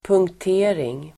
Uttal: [pungkt'e:ring]